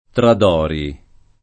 [ trad 0 ri ]